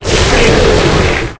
Audio / SE / Cries / SANDACONDA.ogg